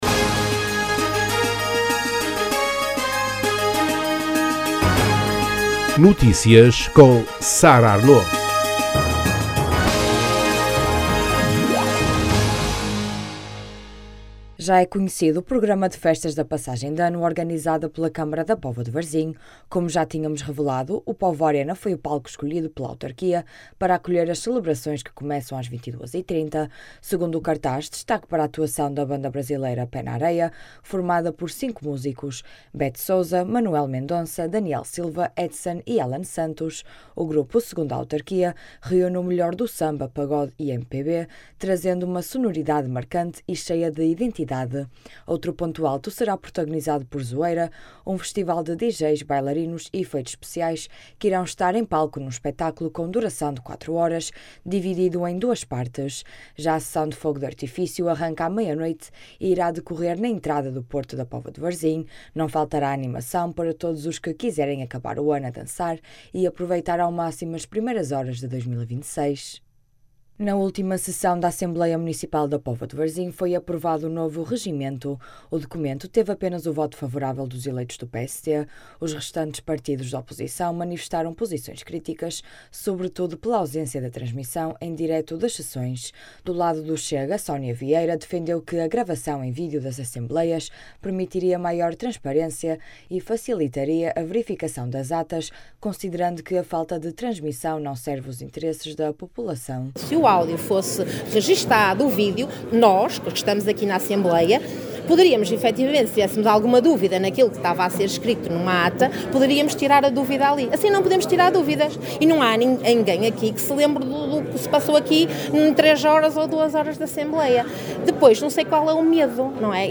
As declarações podem ser ouvidas na edição local.